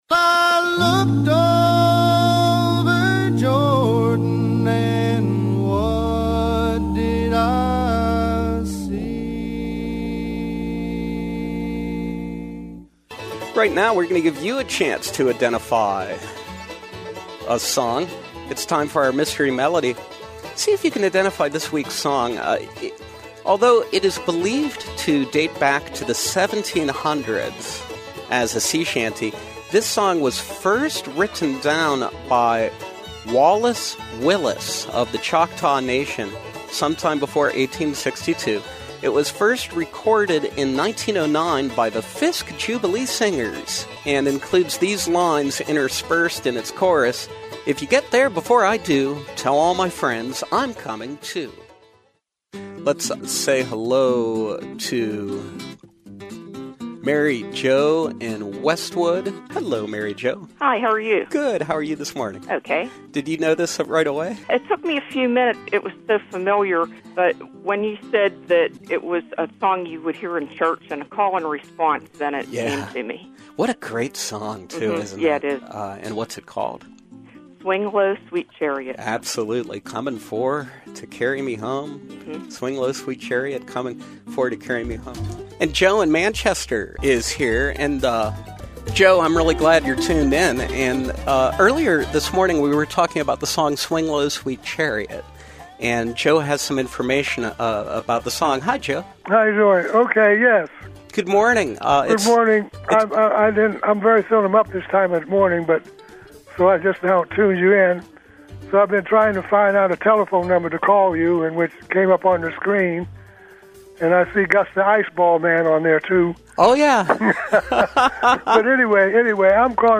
Home » Callers, Featured